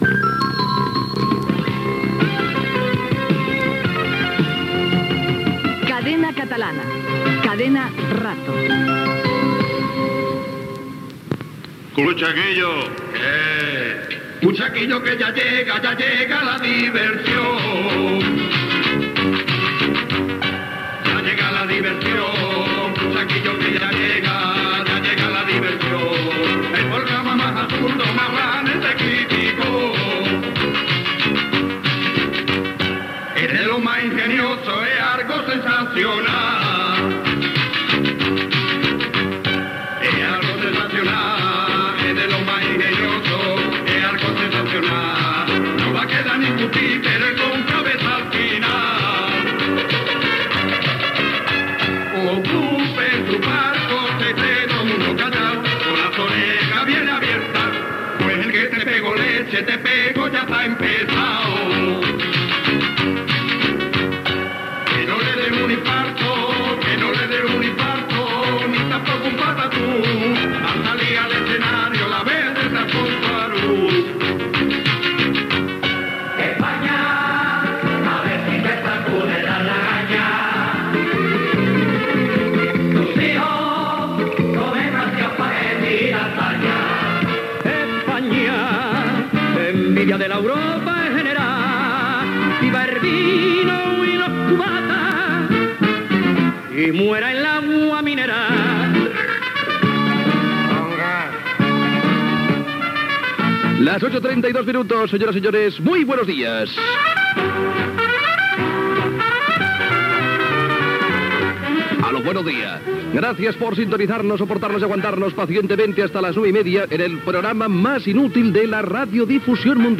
Indicatiu de Cadena Catalana-Cadena Rato.
Entreteniment